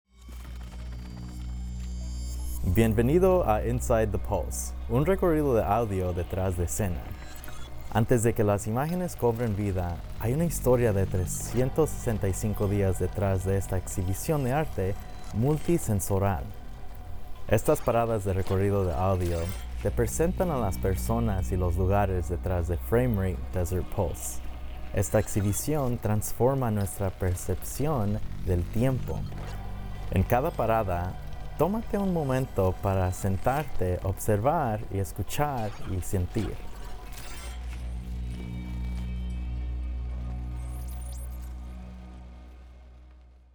Bienvenido al Recorrido de Audio Desert Pulse. Este es un viaje sonoro autoguiado, diseñado para acompañarte mientras te desplazas entre los sitios de las instalaciones.